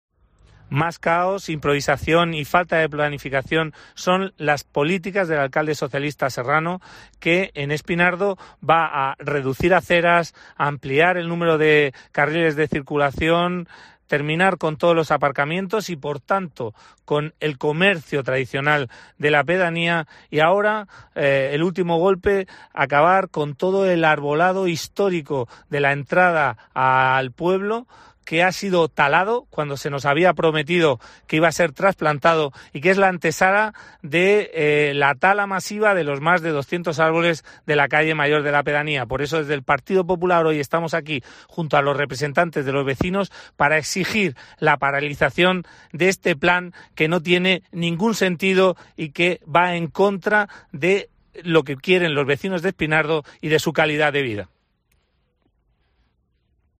José Guillén, concejal del PP en el Ayuntamiento de Murcia